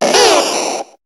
Cri de Bulbizarre dans Pokémon HOME.